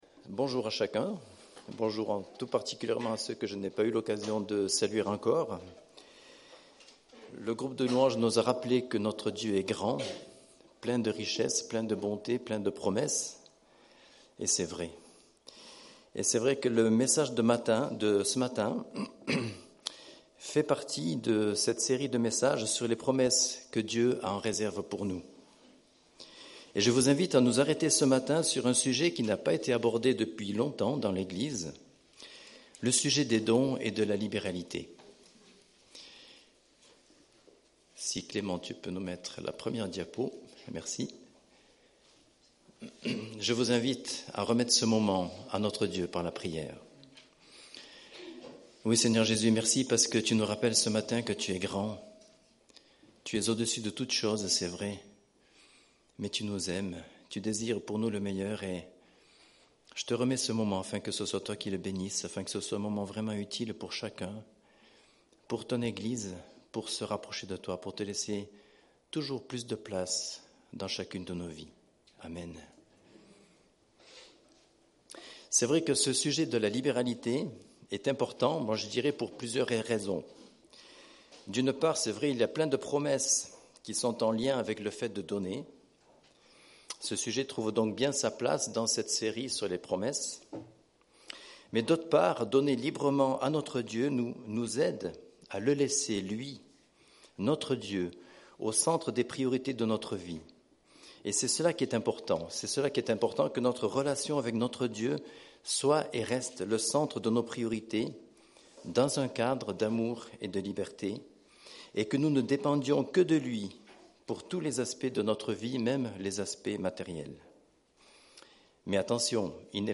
Culte du 03 novembre